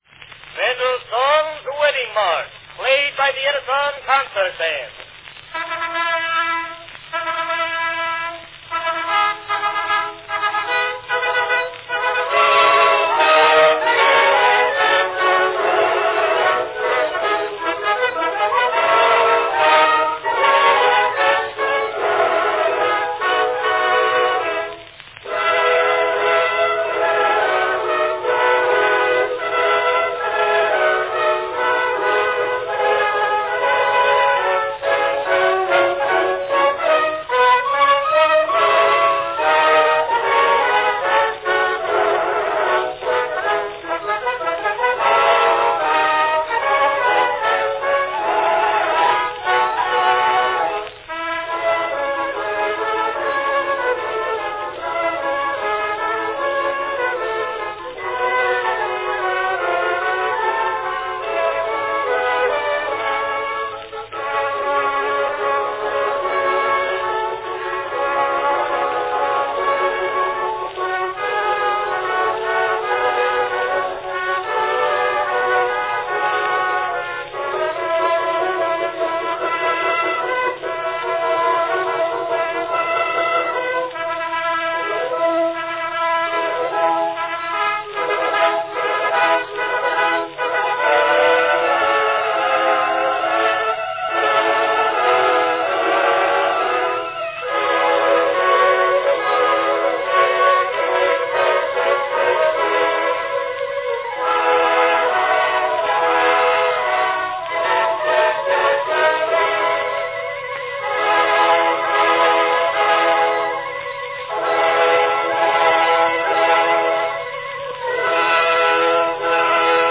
The Edison Concert Band plays Mendelssohn's Wedding March.
Category Band
Performed by Edison Concert Band
Announcement "Mendelssohn's Wedding March, played by the Edison Concert Band."
A fine early recording of a piece of music which evokes a broad range of reactions.